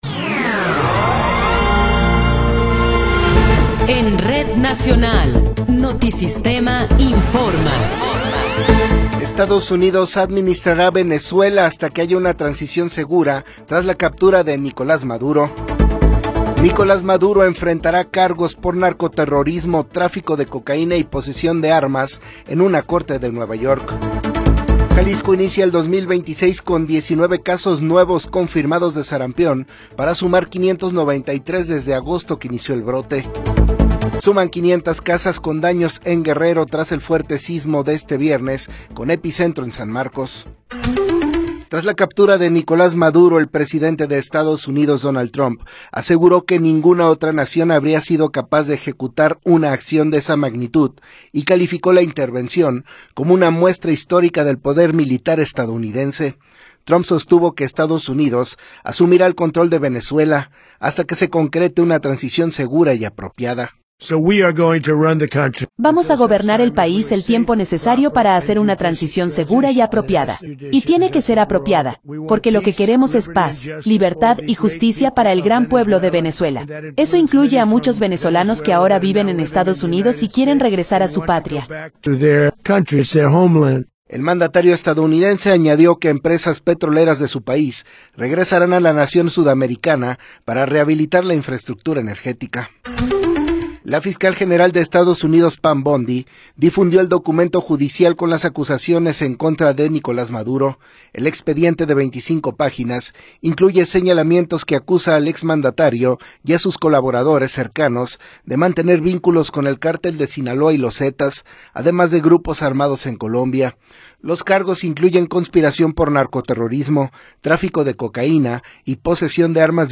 Noticiero 14 hrs. – 3 de Enero de 2026